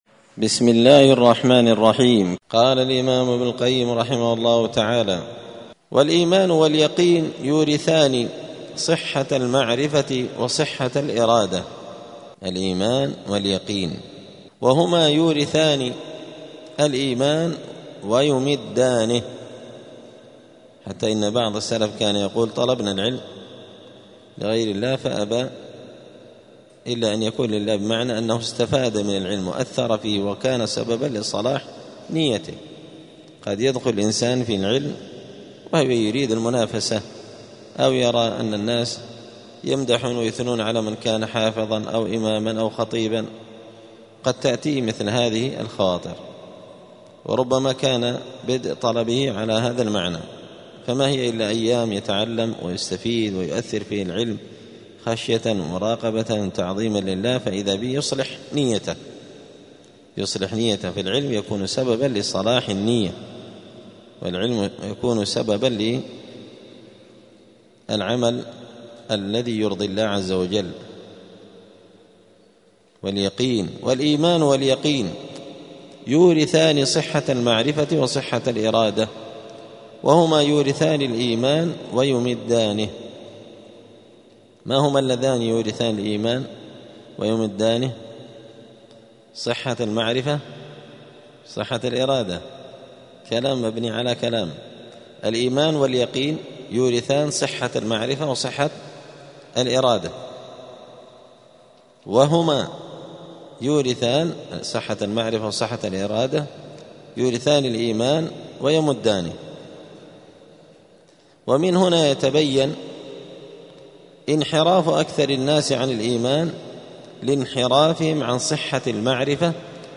*الدرس السابع والأربعون (47) {فصل: الإيمان واليقين يورثان صحة اليقين وصحة الإرادة}*